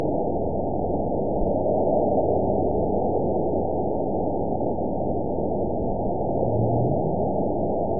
event 920473 date 03/27/24 time 05:10:15 GMT (1 year, 7 months ago) score 9.22 location TSS-AB02 detected by nrw target species NRW annotations +NRW Spectrogram: Frequency (kHz) vs. Time (s) audio not available .wav